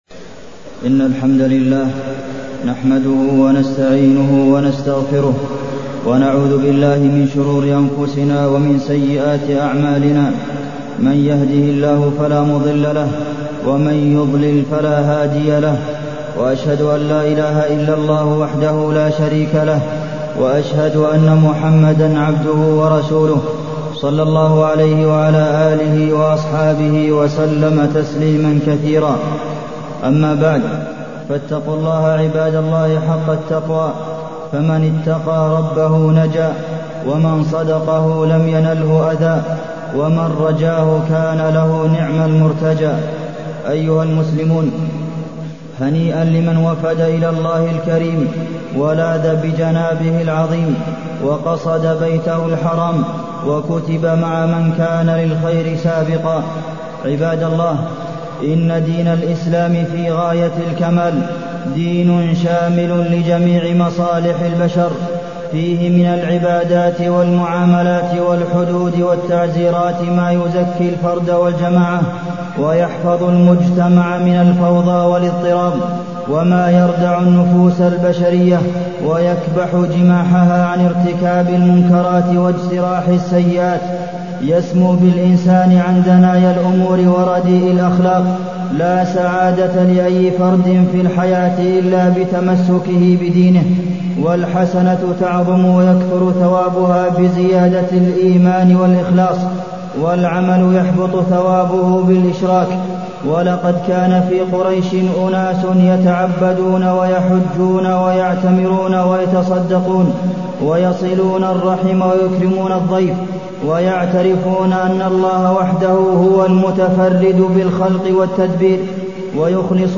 تاريخ النشر ١٤ ذو الحجة ١٤٢١ هـ المكان: المسجد النبوي الشيخ: فضيلة الشيخ د. عبدالمحسن بن محمد القاسم فضيلة الشيخ د. عبدالمحسن بن محمد القاسم أعمال ما بعد الحج